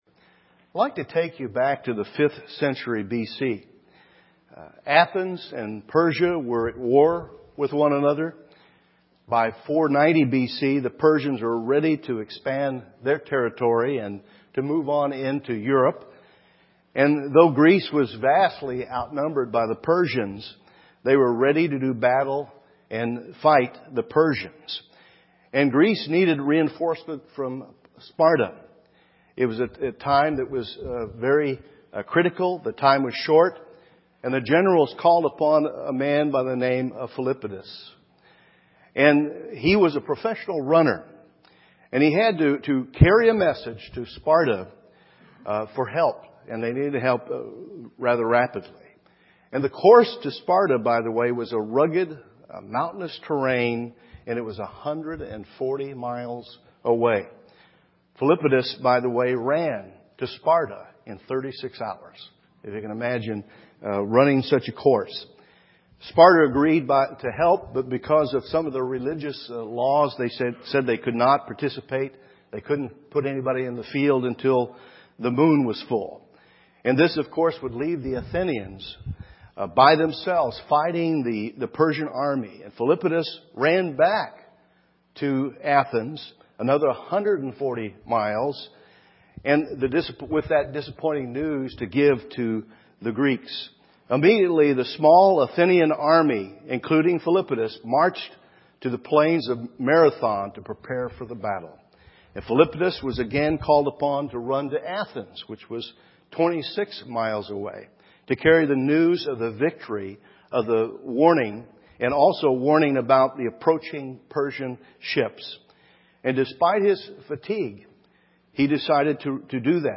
The demands of endurance UCG Sermon Transcript This transcript was generated by AI and may contain errors.